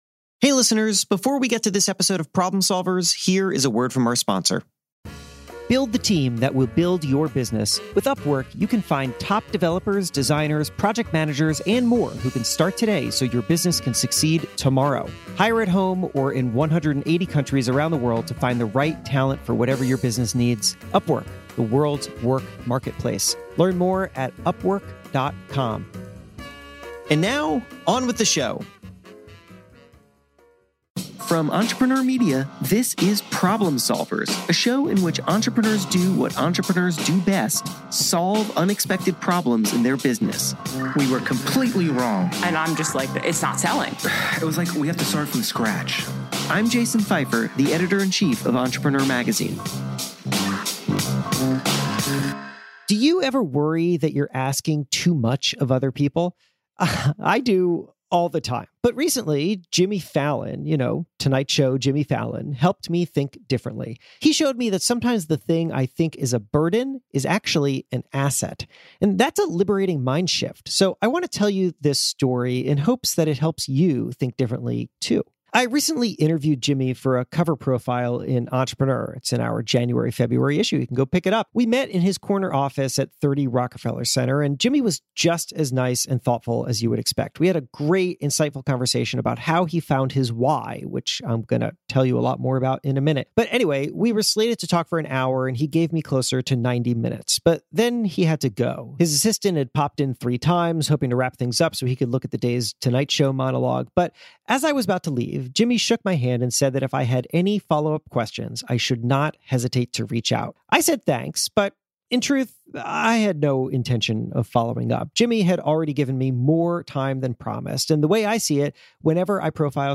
How The Tonight Show host built his career and an entertainment empire — by first identifying his most important mission. This is a reading of the January/February 2022 cover story in Entrepreneur magazine, plus a special behind-the-scenes story about the interview.